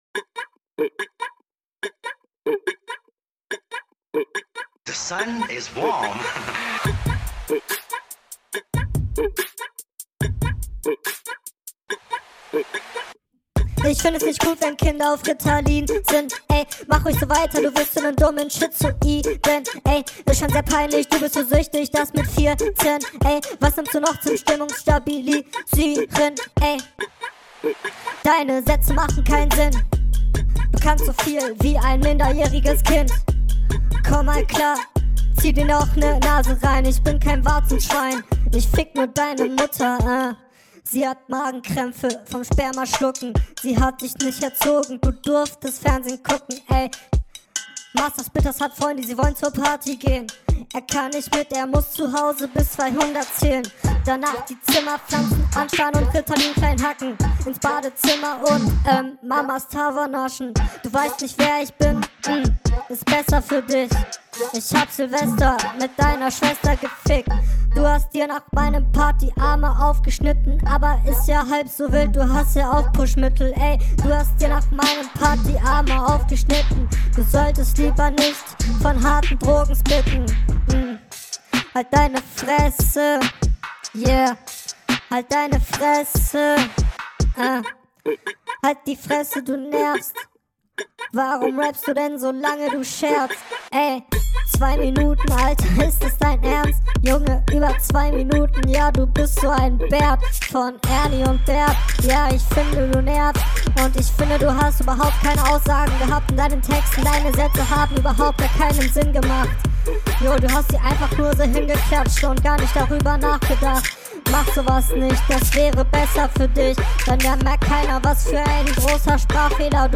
Obwohls gefreestyled ist.
Flow definitiv schwächer.